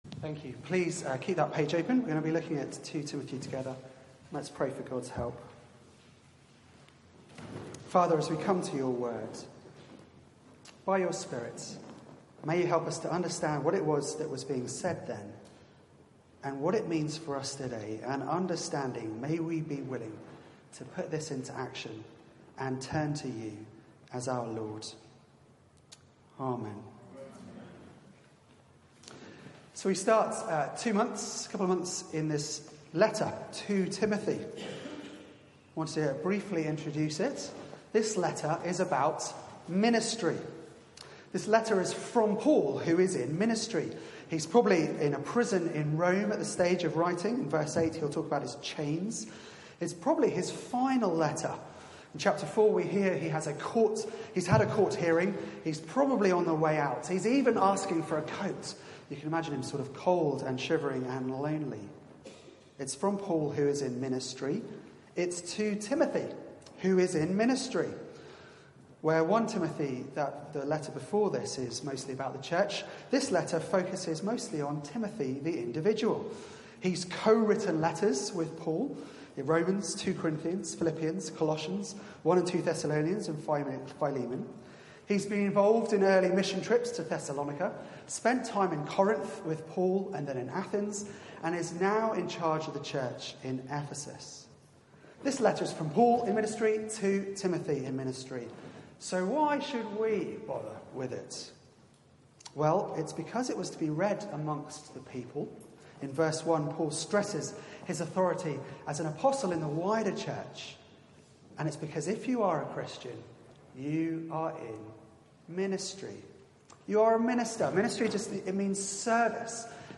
Media for 4pm Service on Sun 02nd Sep 2018 16:00 Speaker